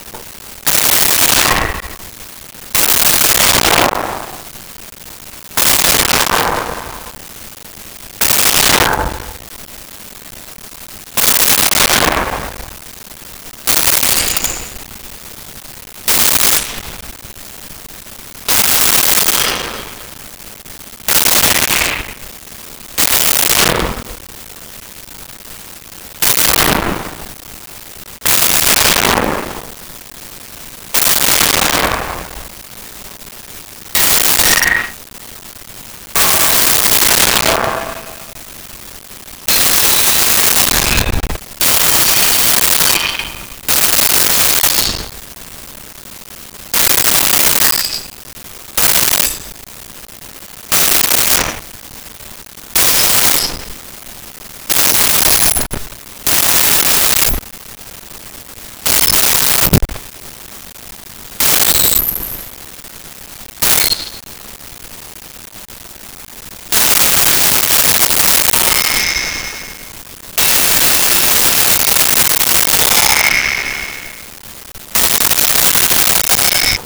Debris 1
Debris_1.wav